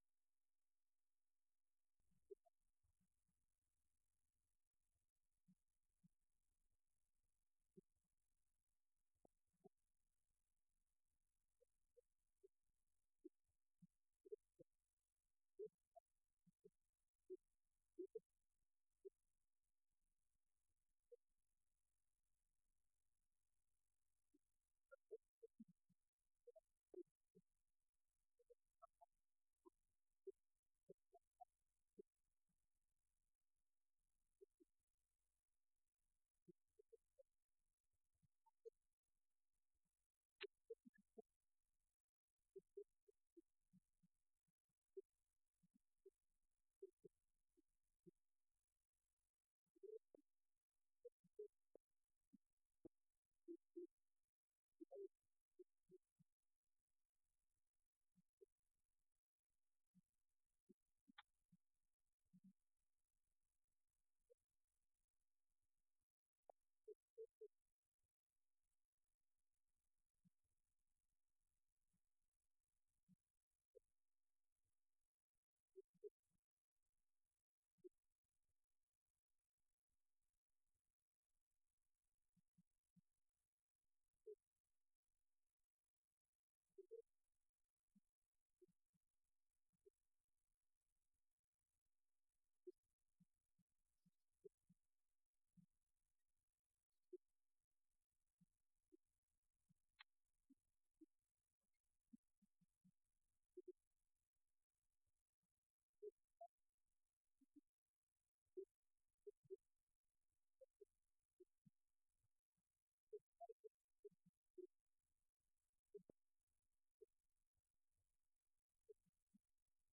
Event: 6th Annual Southwest Spiritual Growth Workshop
lecture